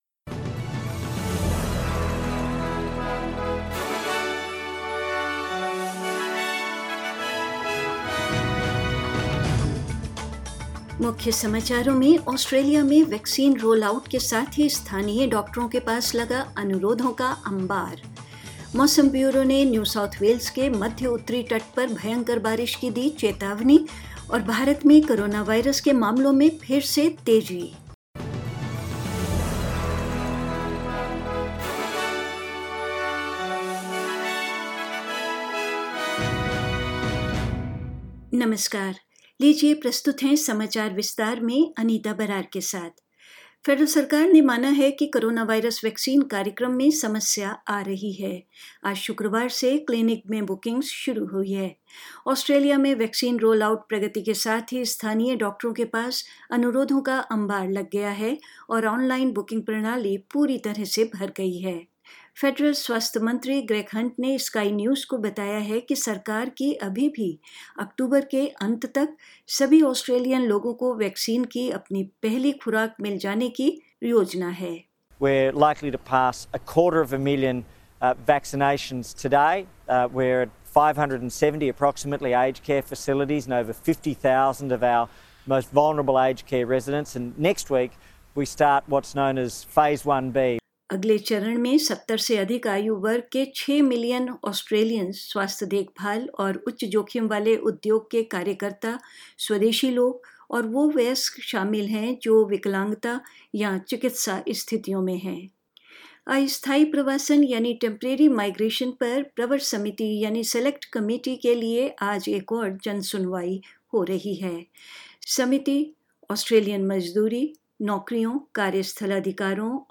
News in Hindi: COVID-19 cases continue to surge in India